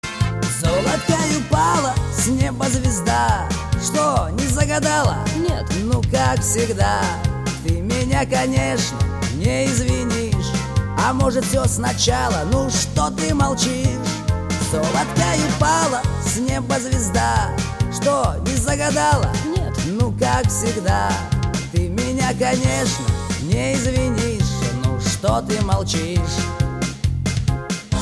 Категория: Нарезки шансона